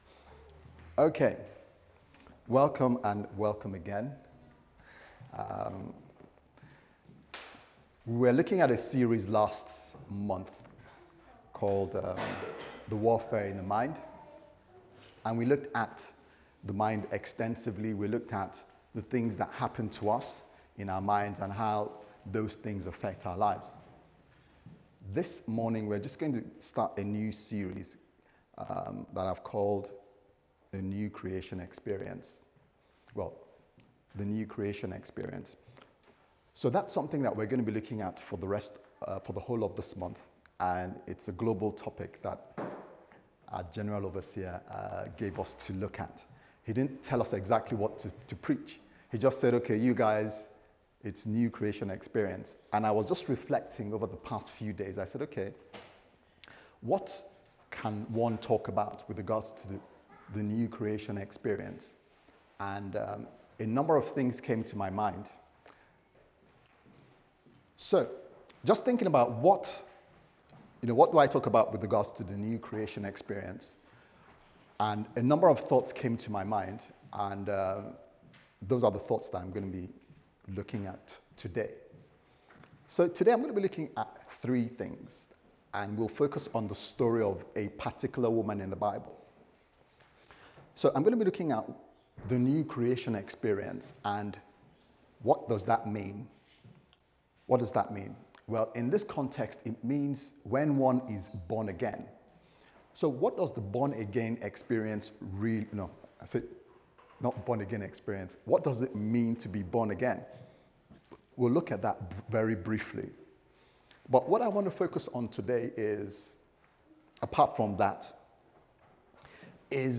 Sunday Service Sermon « The Warfare In Your Mind